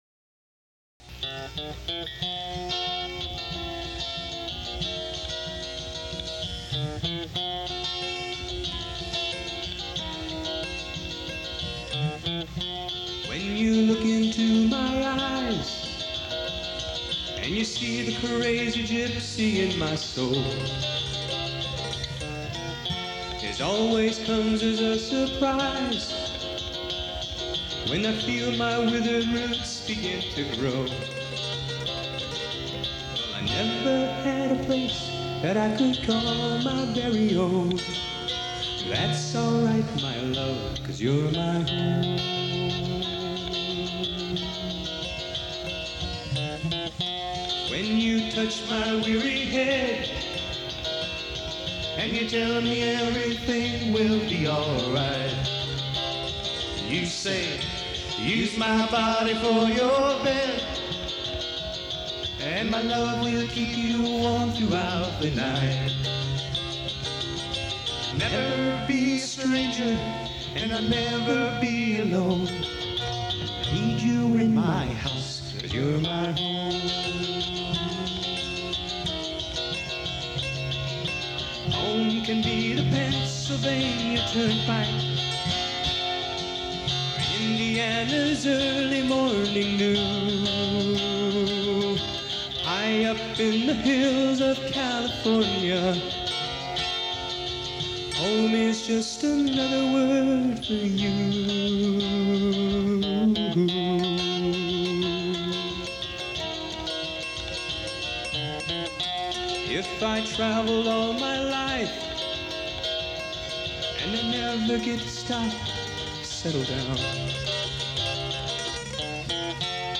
Category Rock/Pop
Studio/Live Live
mandolin, guitar and vocals
bass
guitar and backing vocals
drums and percussion